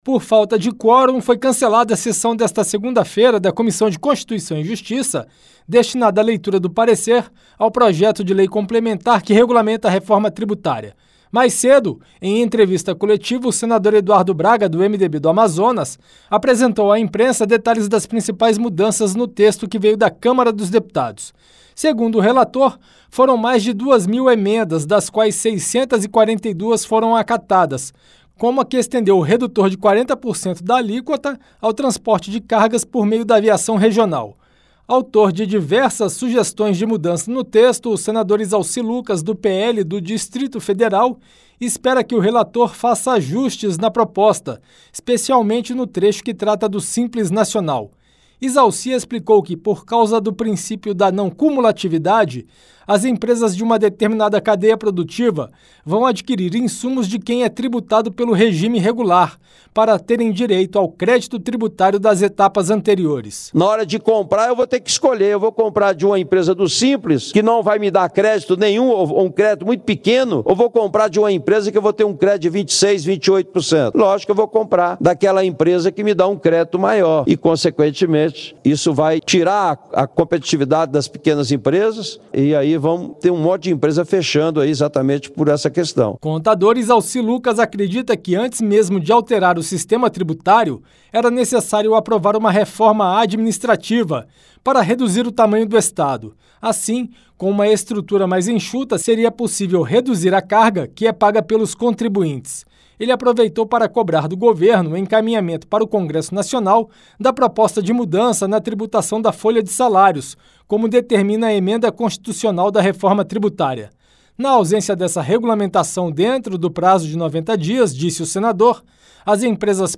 Por falta de quórum, foi cancelada a sessão desta segunda-feira (9), da Comissão de Constituição e Justiça, destinada à leitura do parecer ao projeto de lei complementar que regulamenta a Reforma Tributária (PLP 68/2024). Em plenário, o senador Izalci Lucas (PL - DF), autor de diversas emendas, defendeu ajustes no texto que pode ser votado na Comissão de Constituição e Justiça na próxima quarta-feira (11), especialmente no trecho que trata das empresas optantes do Simples Nacional.